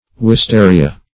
Wistaria \Wis*ta"ri*a\, n. [NL.]